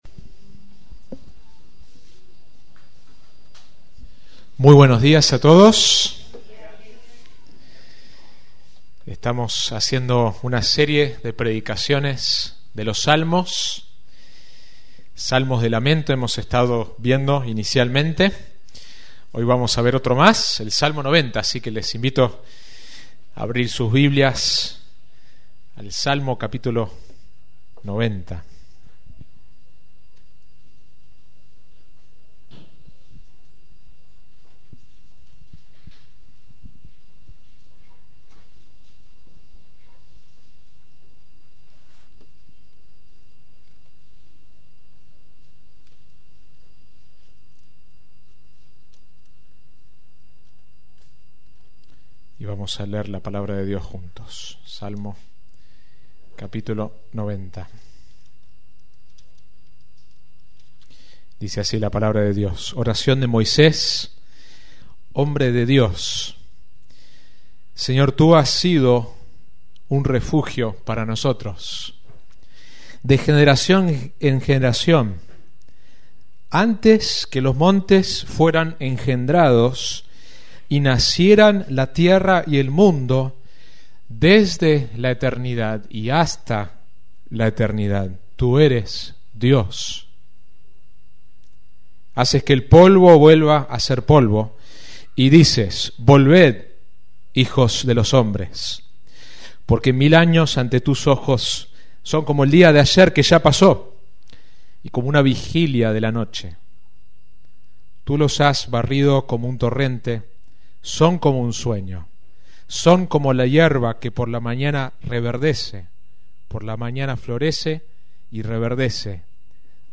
Sermón